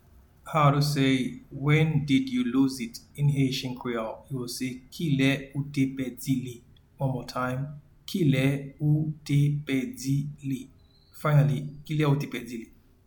Pronunciation and Transcript:
When-did-you-lose-it-in-Haitian-Creole-Kile-ou-te-pedi-li.mp3